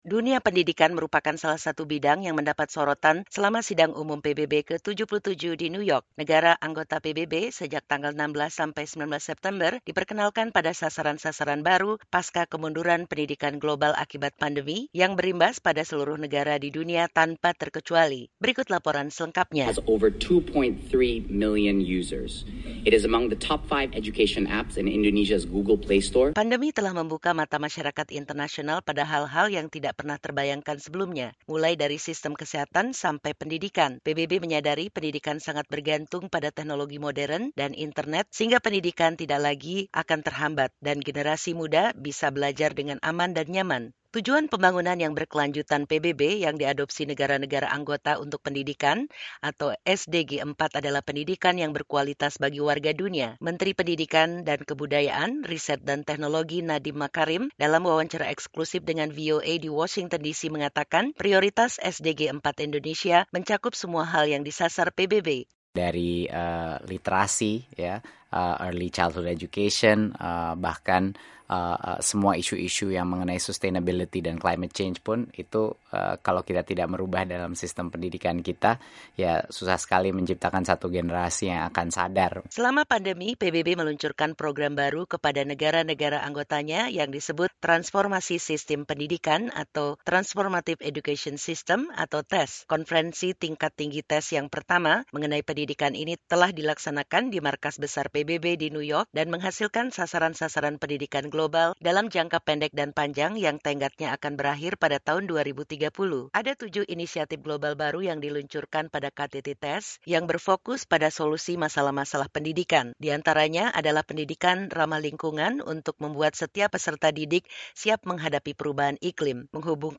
Menteri Pendidikan dan Kebudayaan Riset dan Teknologi Nadiem Makarim dalam wawancara ekslusif dengan VOA di Washington, DC mengatakan prioritas SDG 4 Indonesia mencakup semua hal yang disasar PBB.